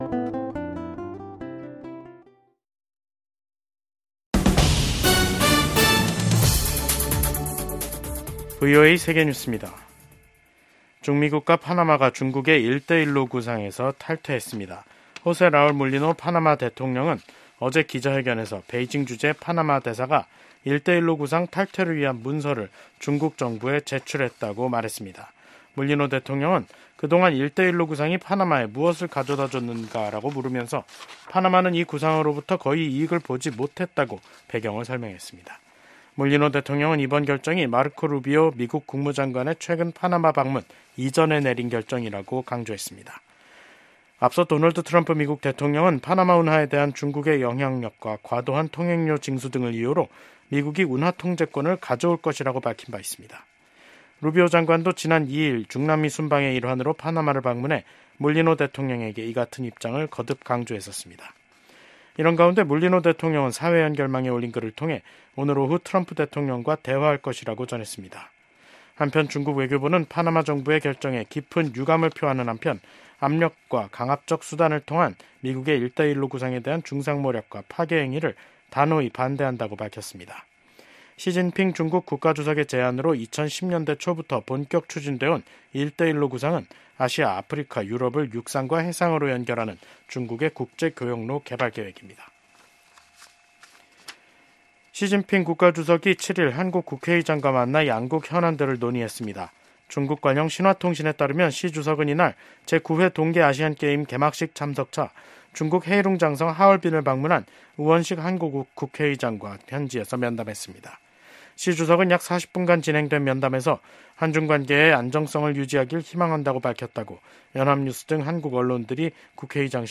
VOA 한국어 간판 뉴스 프로그램 '뉴스 투데이', 2025년 2월 7일 3부 방송입니다. 미국 도널드 트럼프 대통령의 측근인 빌 해거티 상원의원이 미한일 경제 관계는 3국 협력을 지속시키는 기반이 될 수 있다고 강조했습니다. 한국에서 정부는 물론 방산업체 등 민간기업들까지 중국의 생성형 인공지능(AI) 딥시크 접속 차단이 확대되고 있습니다.